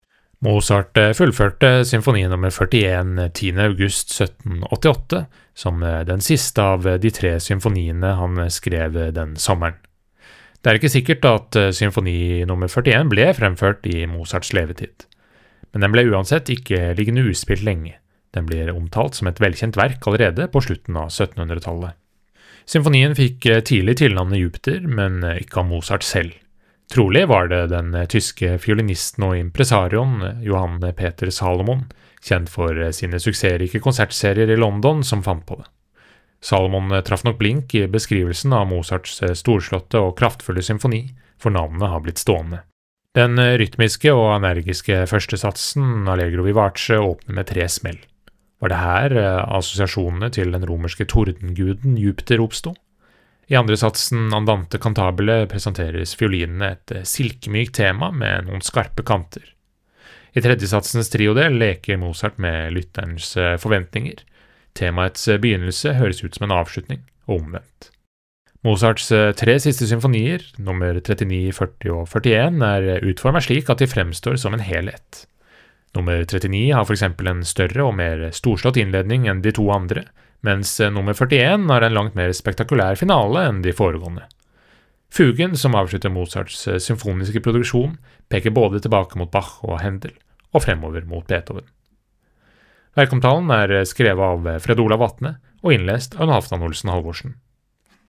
VERKOMTALE: W. A. Mozarts Symfoni nr. 41
VERKOMTALE-W.-A.-Mozarts-Symfoni-nr.-41.mp3